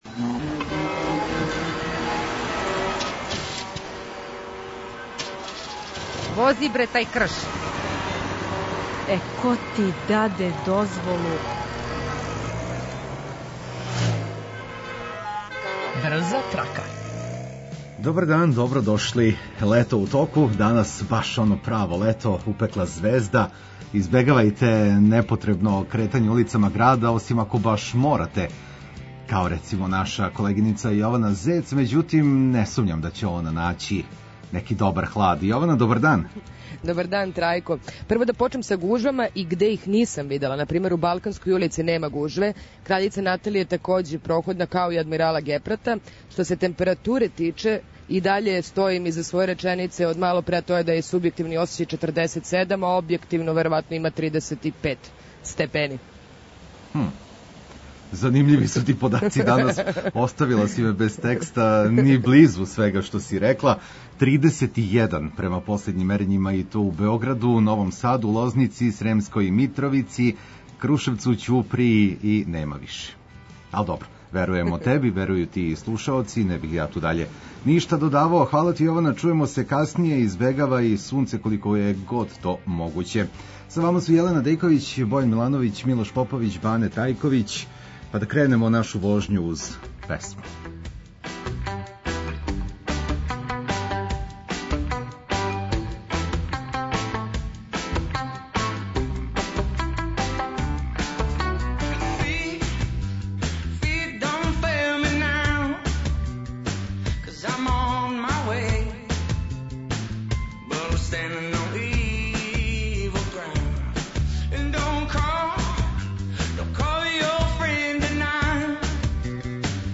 Све то уз сјајну музику и ваше СМС коментаре.